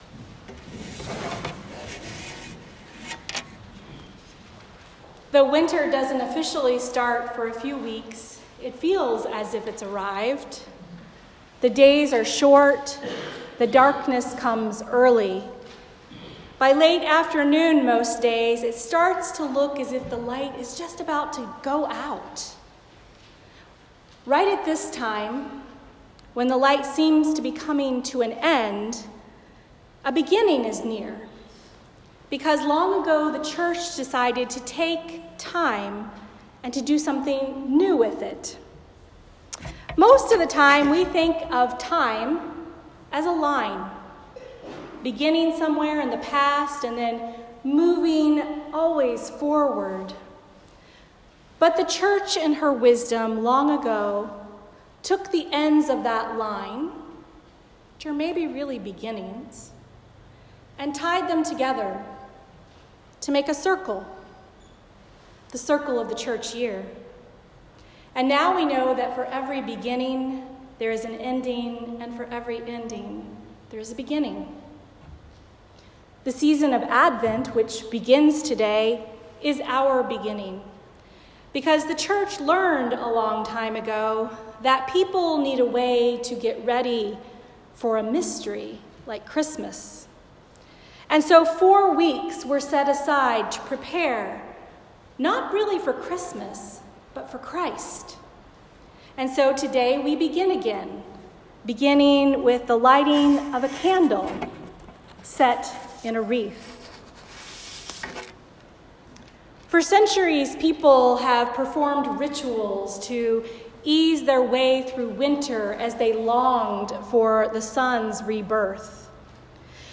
A Sermon for the First Sunday of Advent